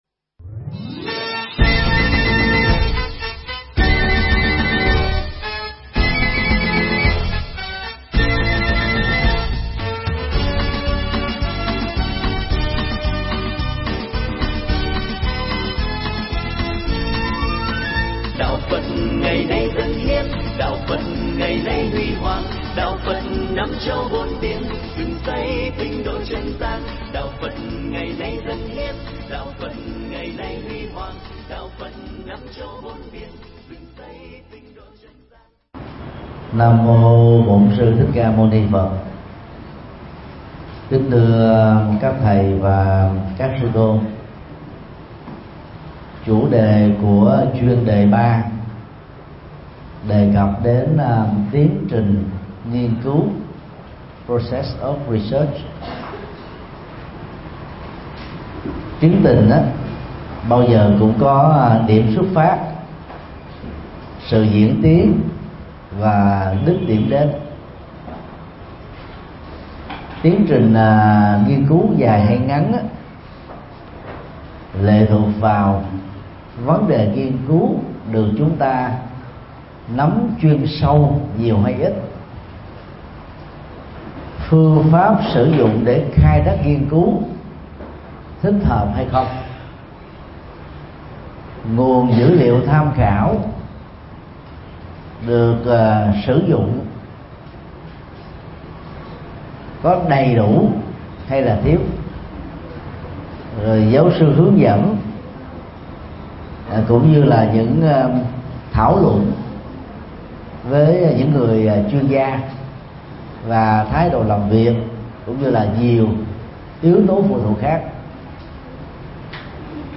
Mp3 Pháp Thoại Phật Pháp Nghiên Cứu Phật Học 3: Tiến Trình Nghiên Cứu – Thượng Tọa Thích Nhật Từ giảng tại HVPGVN cơ sở 1 TPHCM, ngày 24 tháng 10 năm 2017